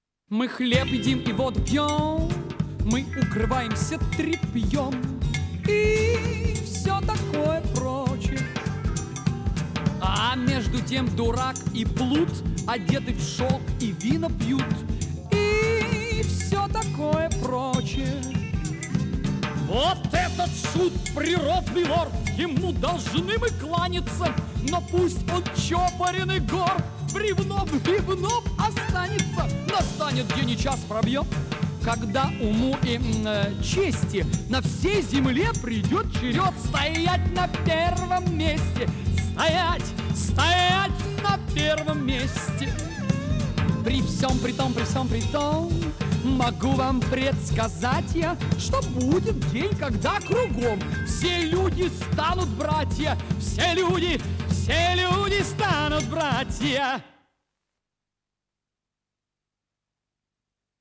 Жанр: Rock, Pop
Стиль: Art Rock, Ballad, Vocal, Classic Rock
Вокальная сюита